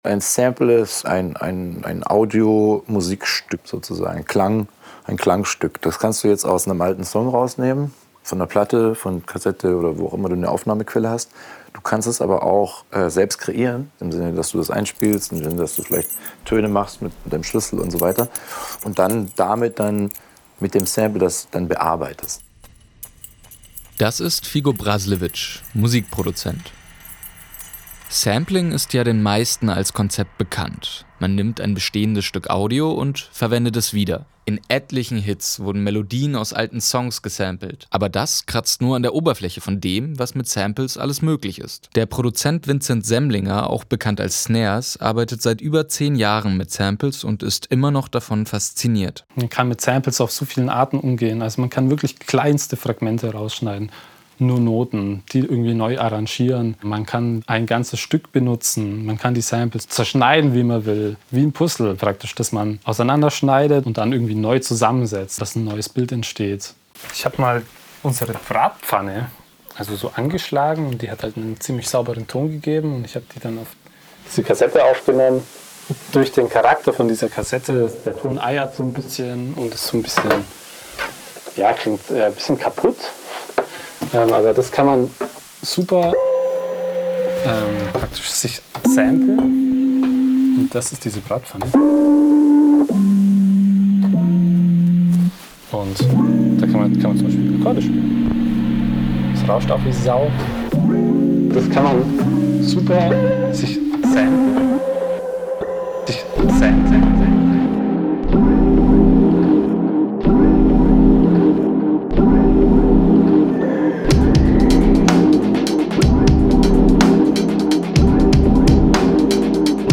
Das Feature versucht, sich diesen Fragen auf seine ganz eigene Art anzunähern.